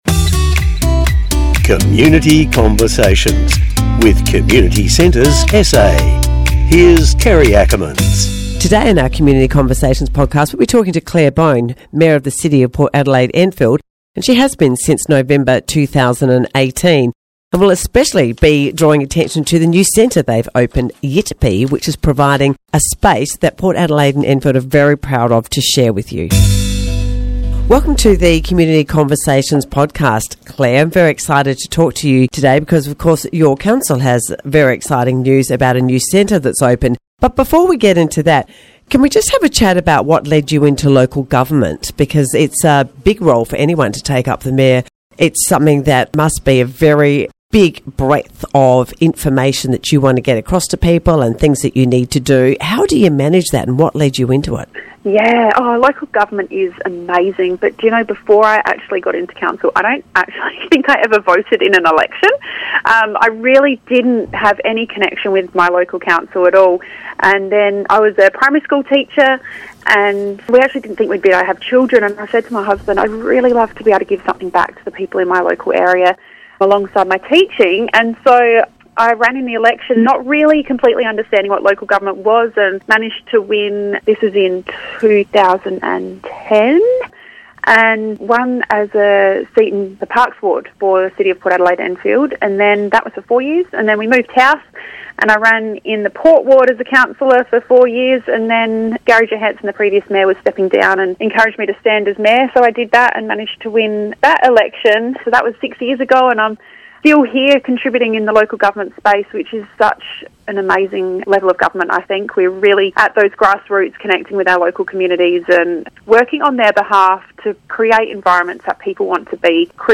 This week, Claire Boan, the Mayor of Port Adelaide Enfield Council, shares her journey into local government while emphasizing the vital importance of community engagement and grassroots connections. During our conversation, she discusses how these connections help shape stronger, more resilient communities.